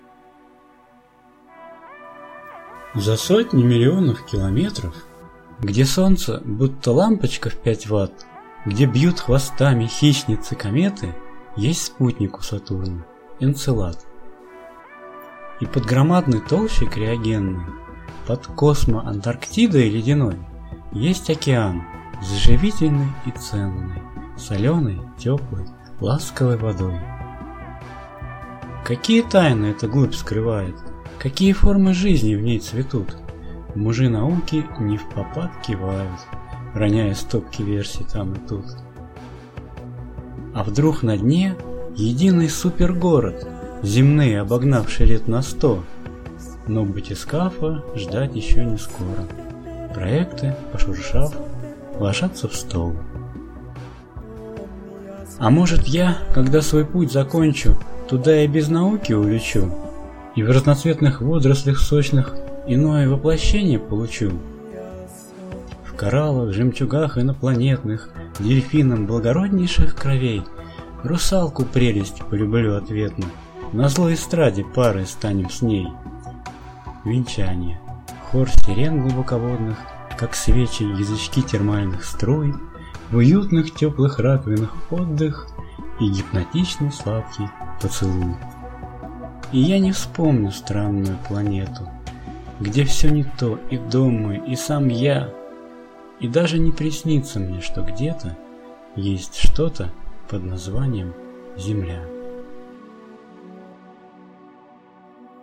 Прослушать в авторском исполнении: